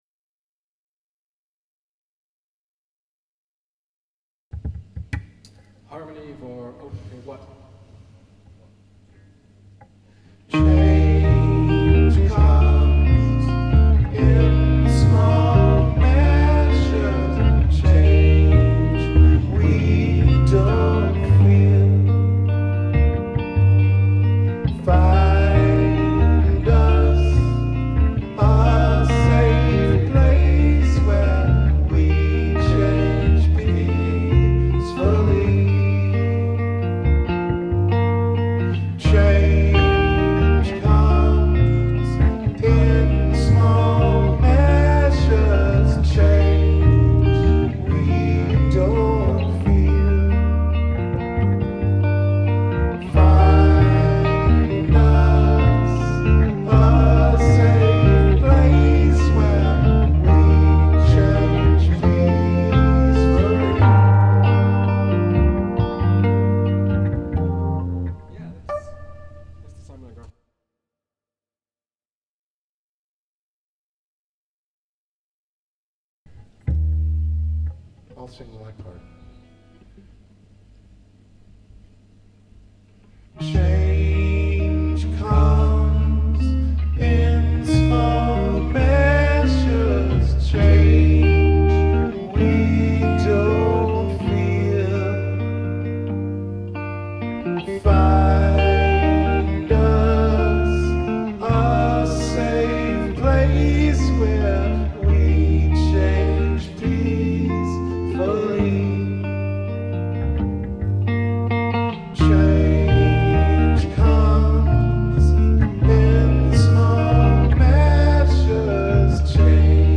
Two harmony rehearsings and the song after each other. It was not a perfect take 😉 .. but sure funny